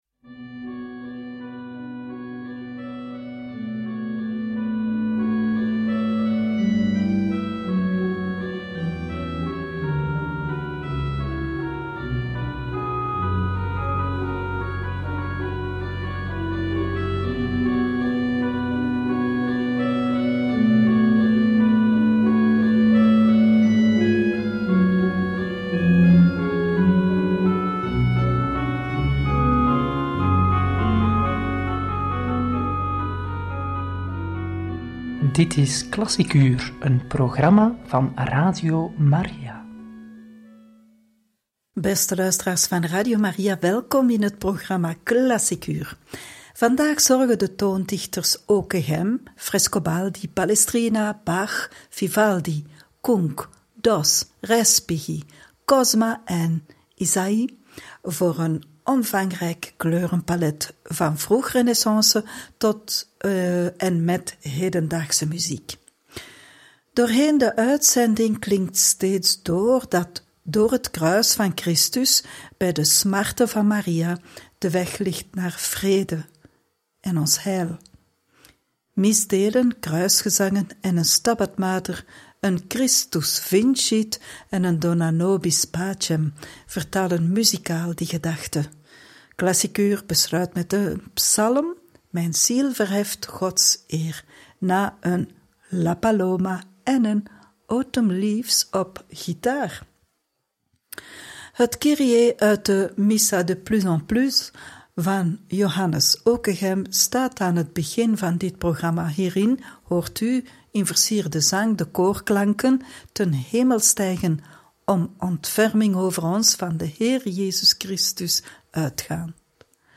Een omvangrijk kleurenpallet, van vroeg-renaissance tot en met hedendaagse muziek – Radio Maria